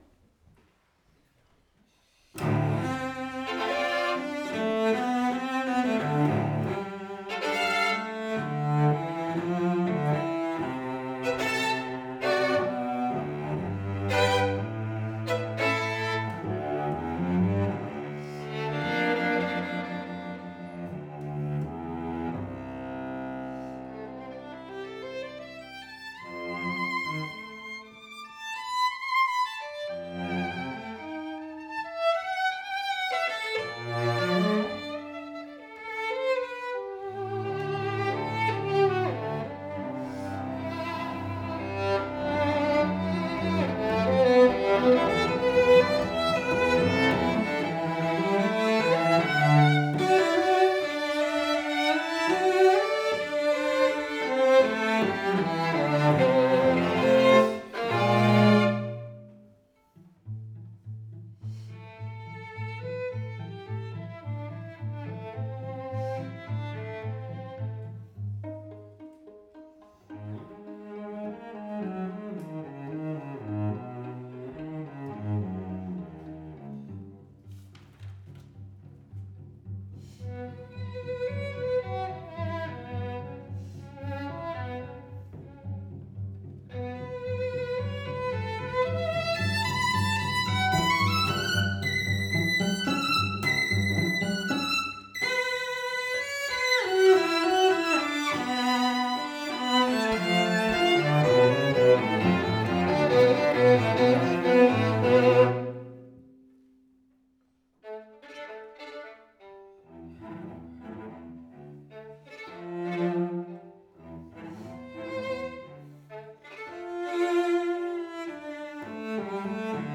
Venue: Bantry Library
vc Instrumentation Category:Duo Artists
cello
violin